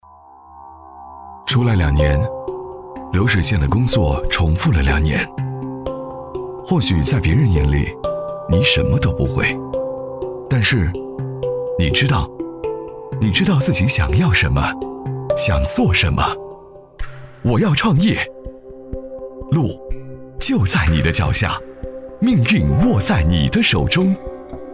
走心旁白微电影 | 声腾文化传媒
B【旁白】走心独白男6.mp3